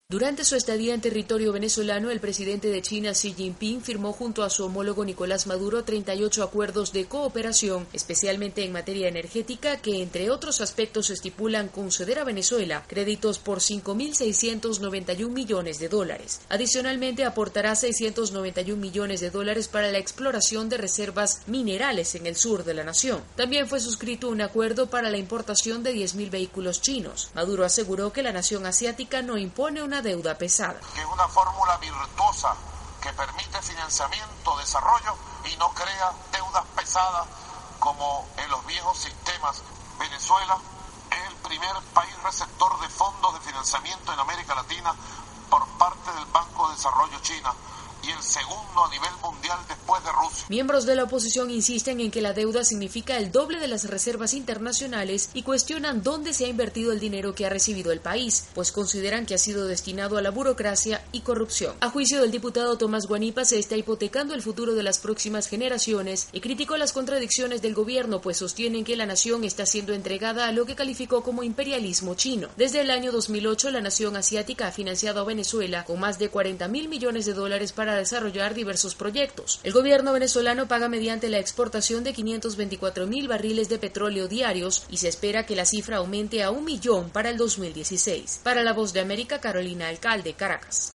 El gobierno de Venezuela incrementa su deuda con China en medio de críticas. Desde Caracas informa